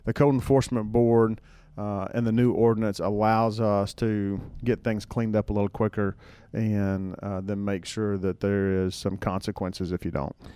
The city of Madisonville now has a code enforcement board.  Mayor Kevin Cotton says it’s job is to make decisions about private property and its connection to the city’s appearance.
kevin-cotton-0210a.mp3